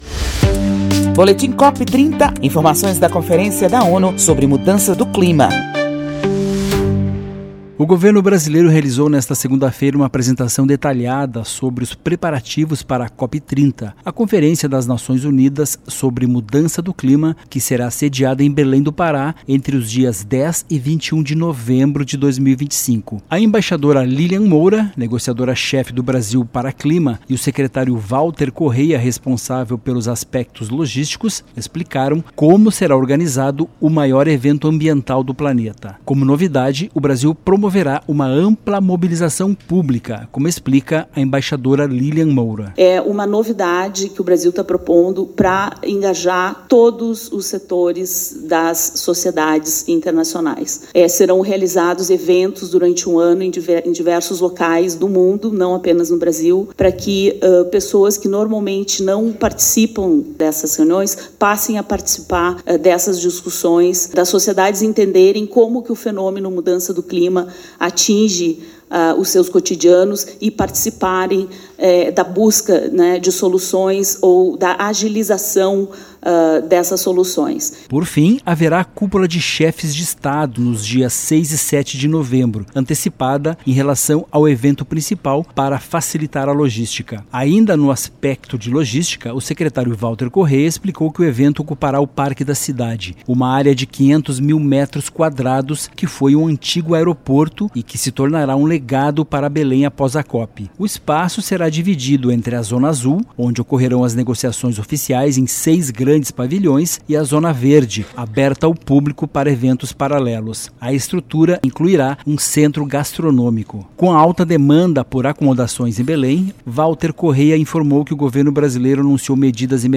Com vista à Conferência em novembro, a empresa binacional leva metodologia consagrada em gestão de resíduos sólidos para Belém, cidade-sede do evento. Reformas de Unidades de Valorização de Recicláveis garantirão mais eficiência à reciclagem e melhores condições aos catadores. Ouça a reportagem especial.